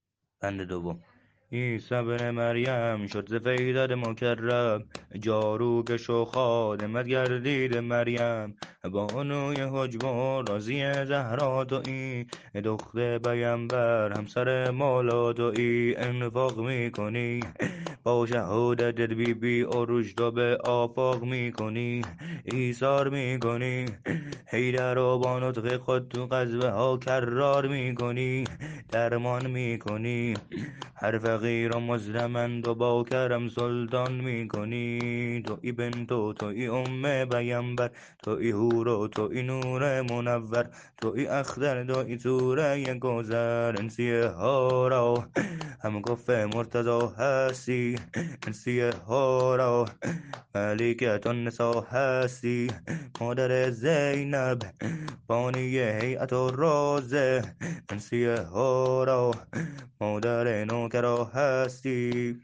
شور شهادت حضرت فاطمه زهرا (س) -(عصمتِ کبرا تویی همسر به مولا)